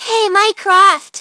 synthetic-wakewords
ovos-tts-plugin-deepponies_Nanako Dojima_en.wav